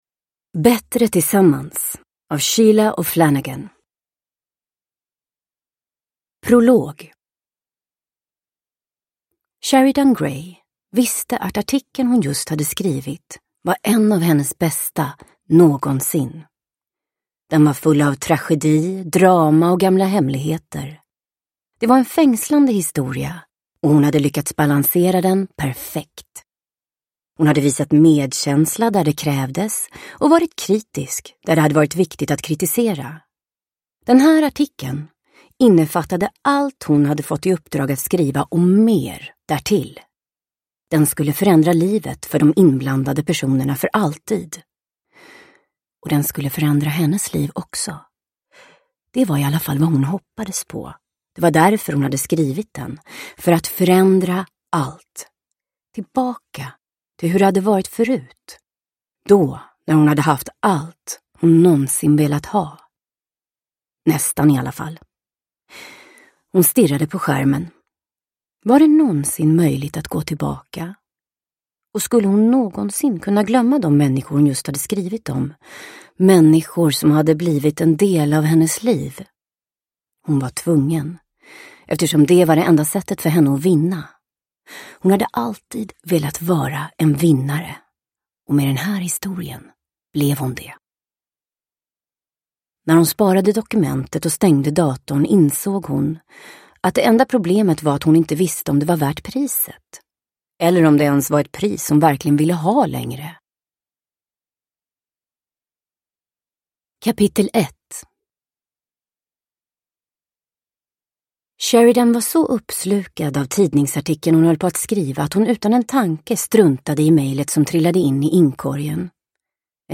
Bättre tillsammans – Ljudbok – Laddas ner